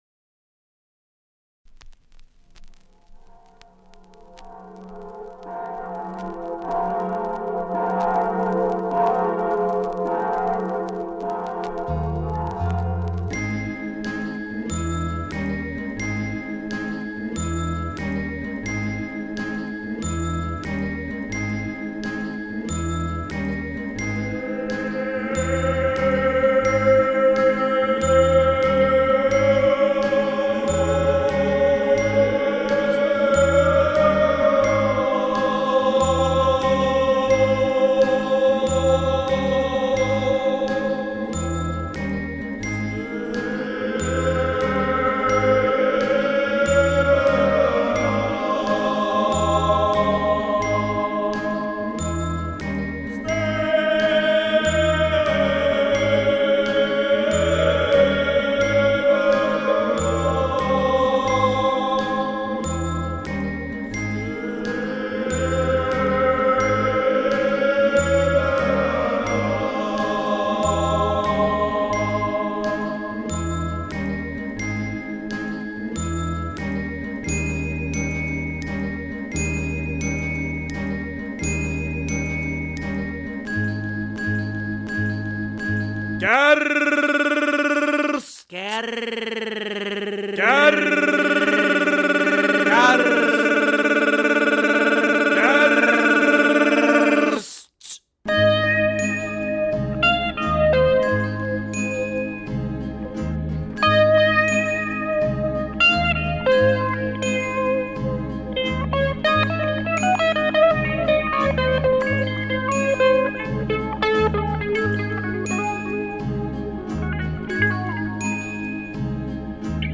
voice, tapes
guitar